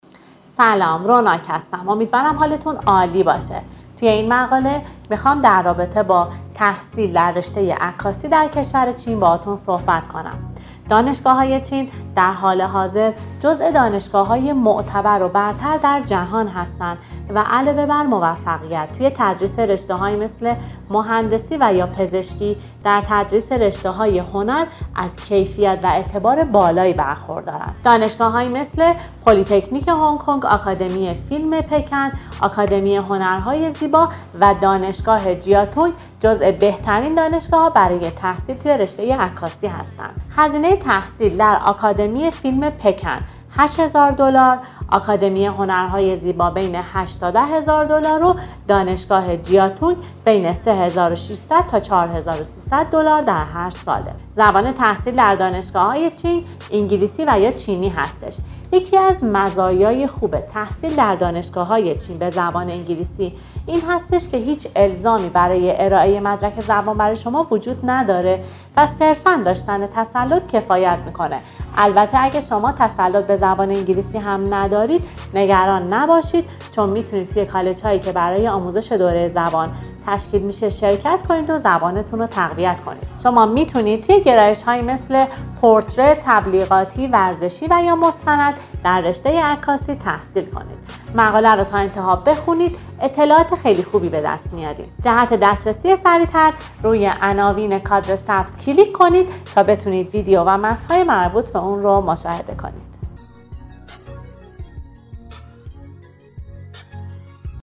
در این ویدیو خلاصه تحصیل این رشته را از زبان همکار ما شنیده و برای هرگونه جزئیات بیشتر با مشاورین ما در ارتباط باشید.